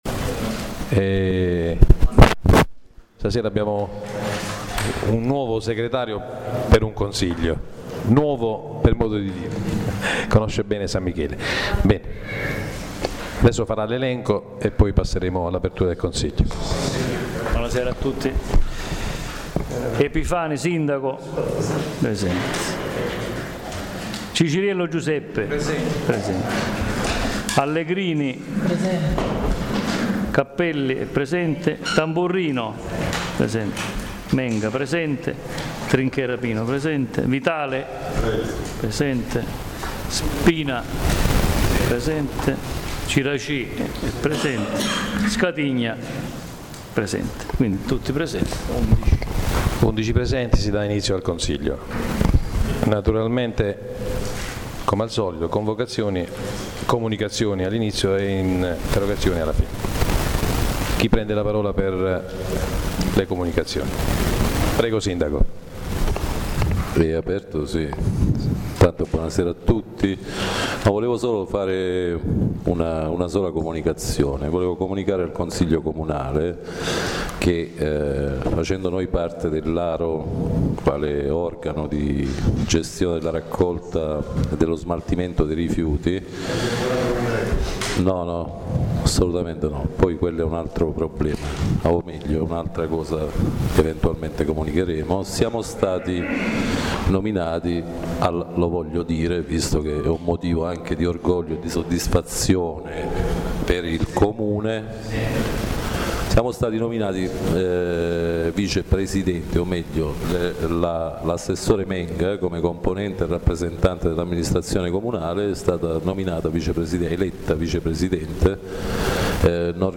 La registrazione audio del Consiglio Comunale di San Michele Salentino dell’11/07/2013
Un ringraziamento sempre al Presidente del Consiglio Comunale Pino Trinchera per averci dato la possibilità di registrare direttamente dal mixer, il che migliora la qualità della registrazione audio. —— I lavori del Consiglio Comunale si sono espletati in circa 2 ore e la maggior parte del tempo è stato impiegato nelle discussioni riferite a comunicazioni ed interrogazioni su vari temi: Vice presidenza dell’ARO; finanziamenti vari per opere pubbliche; risorse premiali sulla raccolta differenziata; abbandono spazzatura sulle strade extraurbane; riattivazione bagni pubblici; pagamento tasse comunali da parte di consiglieri comunali; l’informazione sul giornale “Il Comune informa”; spese legali; raccolta oboli festa patronale.